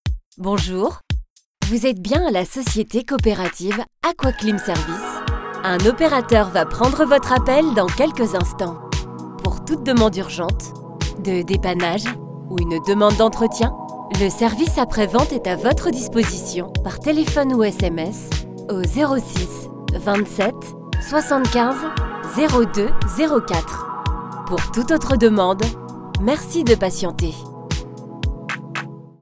🎙 Voix-off française – Douce, sincère et naturelle
Système téléphonique : Aquaclim
Ma voix est jeune, douce, sincère, avec un léger grain qui apporte chaleur et authenticité à chaque projet.
attente-telephonique-aquaclim.wav